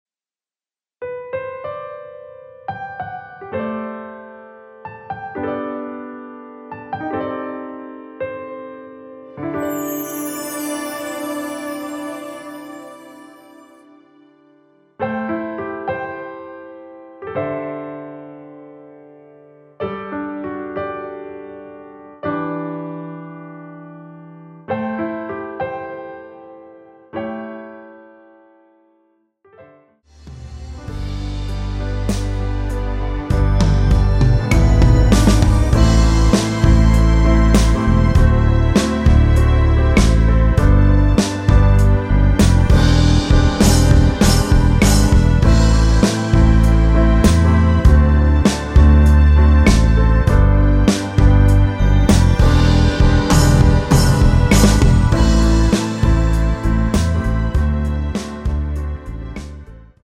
내린 MR
◈ 곡명 옆 (-1)은 반음 내림, (+1)은 반음 올림 입니다.
앞부분30초, 뒷부분30초씩 편집해서 올려 드리고 있습니다.
중간에 음이 끈어지고 다시 나오는 이유는